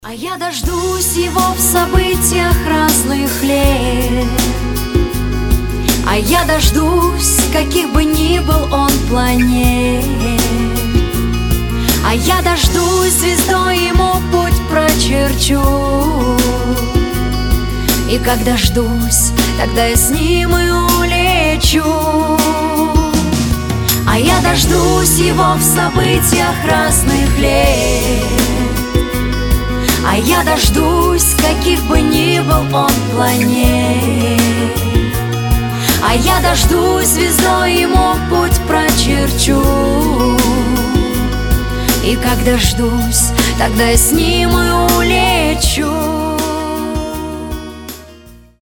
• Качество: 320, Stereo
женский вокал
спокойные
русский шансон
лиричные